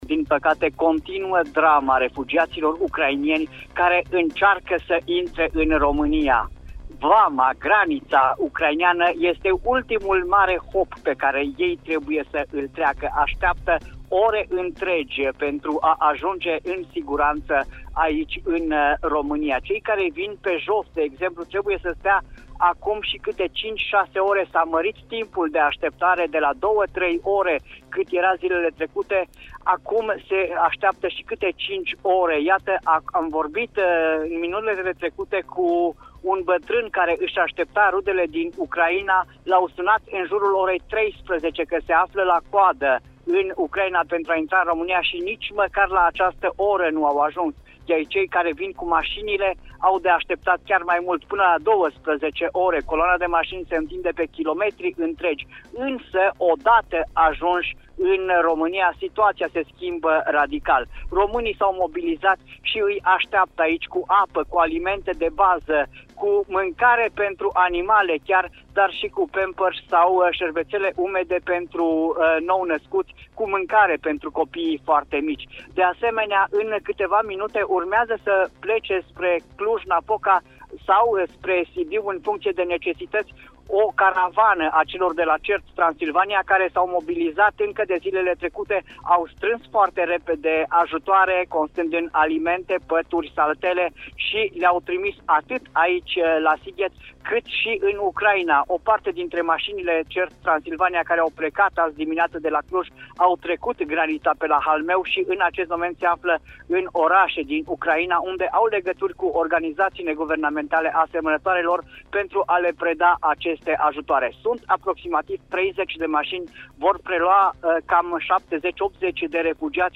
Corespondență de la PTF Sighet: drama refugiaților din Ucraina continuă! [AUDIO]